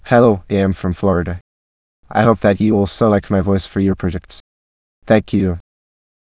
voices/VCTK_American_English_Males at main